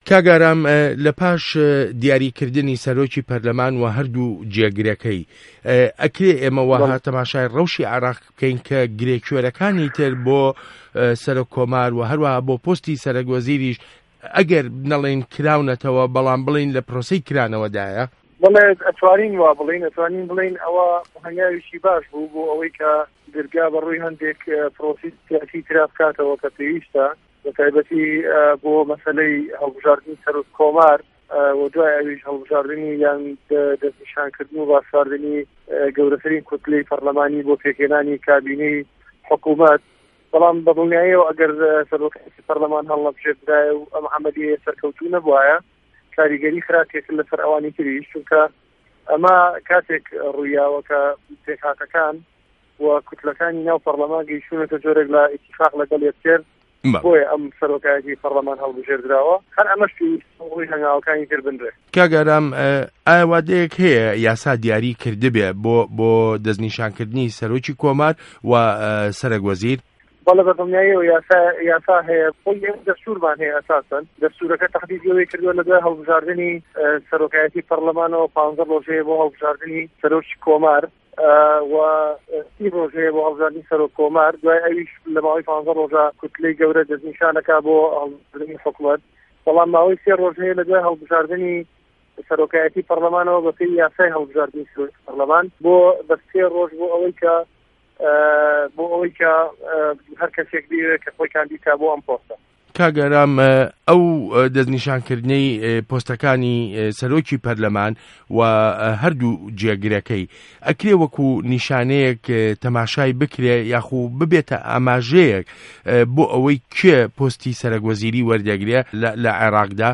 وتووێژ له‌گه‌ڵ ئارام شێخ موحه‌مه‌د